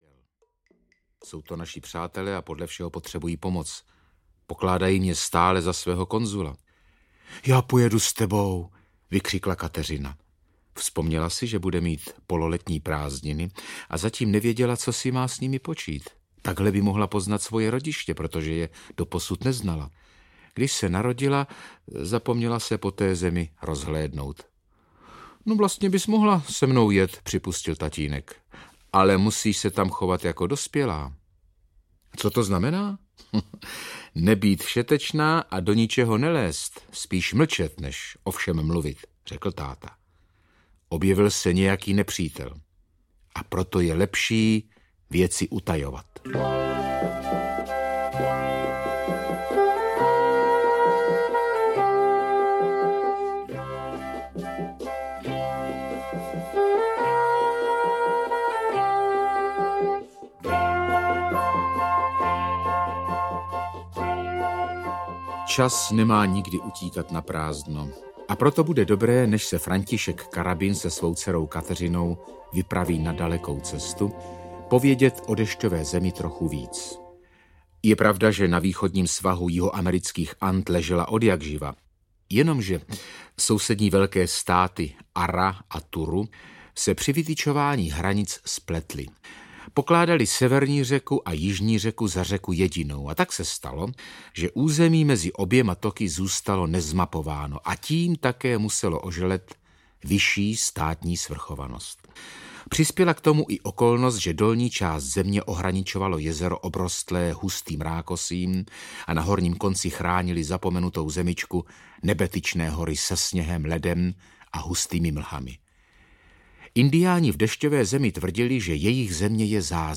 Indiánská romance audiokniha
Ukázka z knihy
• InterpretLuděk Munzar